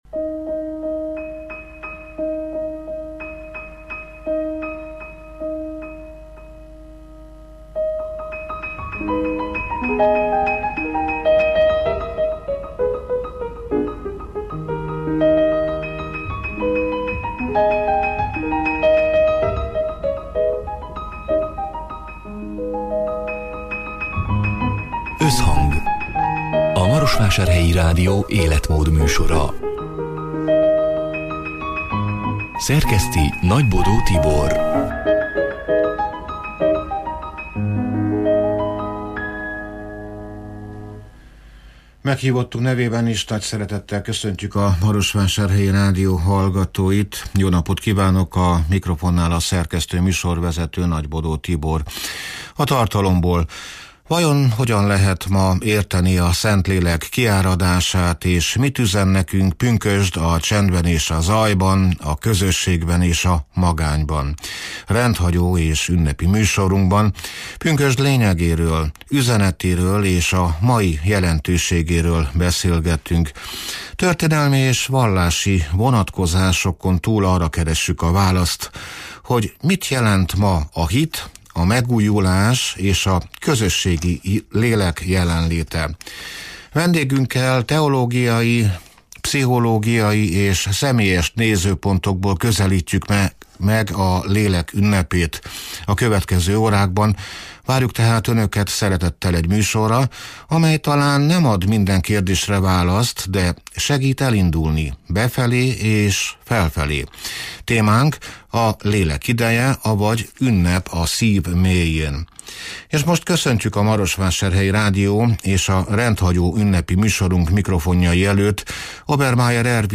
(elhangzott: 2025. június 4-én, szerdán délután hat órától élőben)